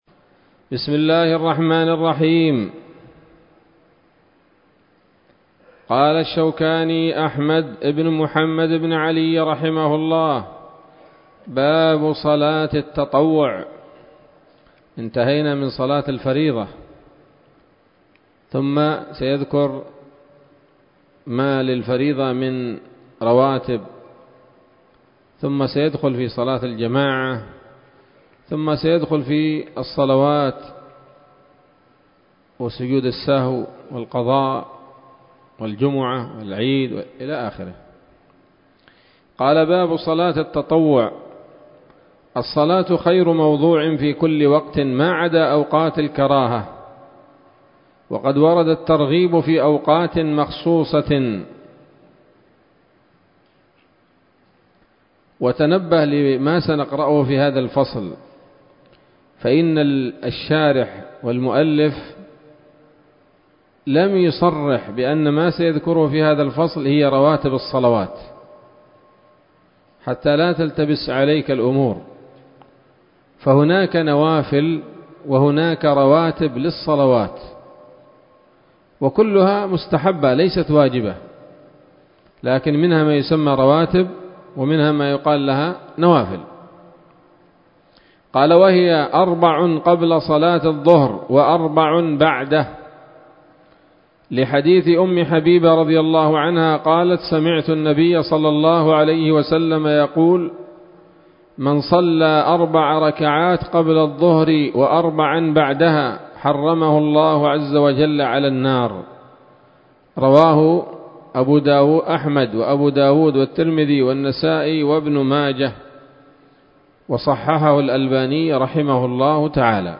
الدرس العشرون من كتاب الصلاة من السموط الذهبية الحاوية للدرر البهية